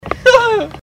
Laugh 27